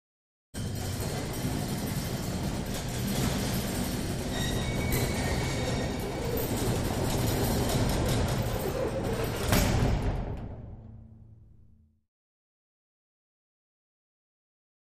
Old Manual Metal Gate Rolls Slowly Open And Close With Thumps And Rattles.